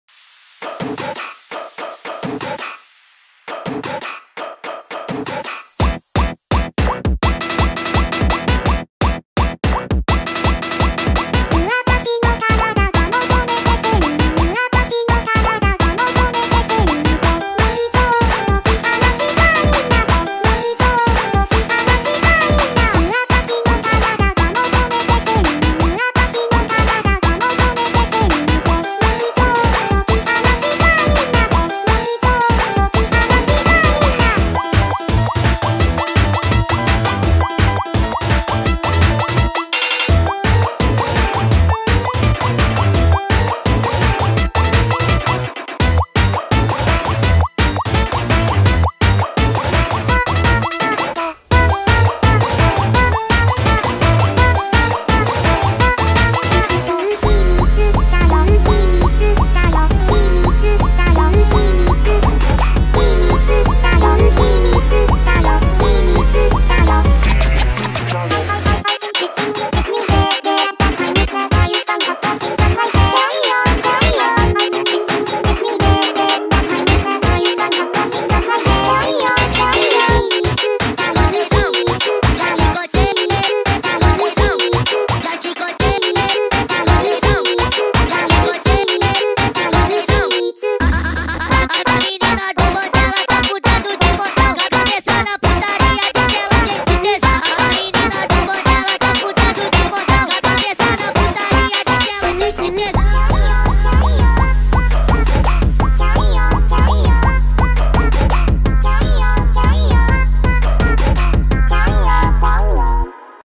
دانلود فانک با ریتم تند در نسخه Sped Up
فانک